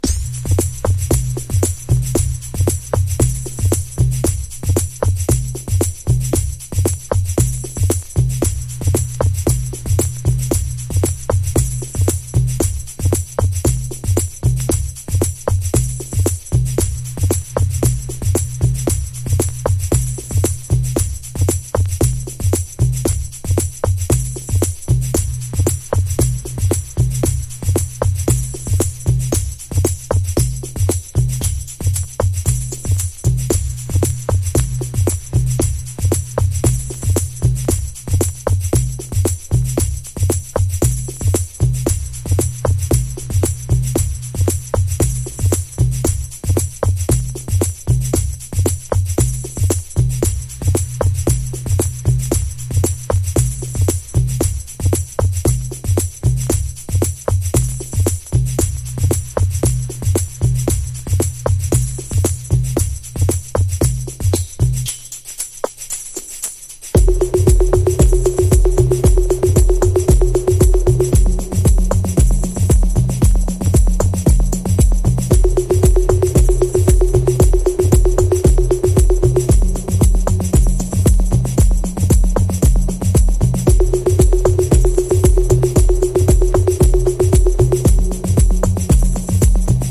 ELECTRO HOUSE / TECH HOUSE